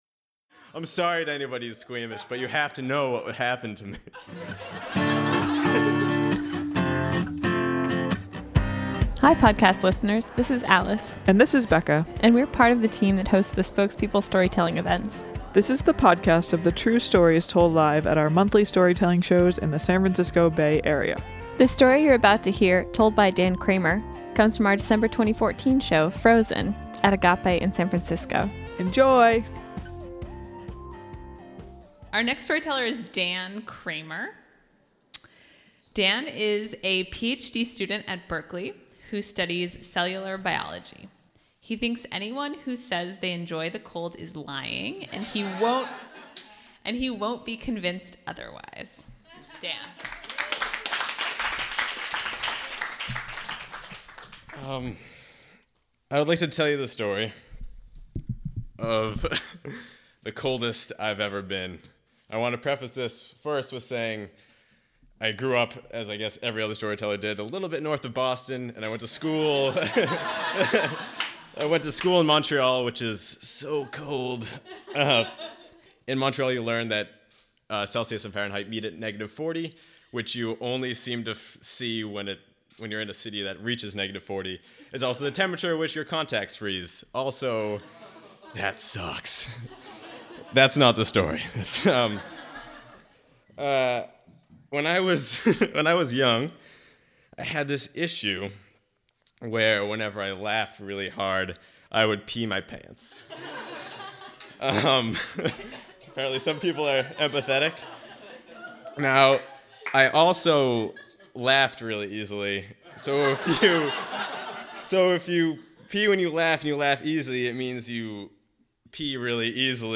Homepage / Podcast / Storytelling
This story of one moist night in Massachusetts was told live at our December 2014 show, “Frozen.”